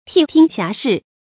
逖听遐视 tì tīng xiá shì
逖听遐视发音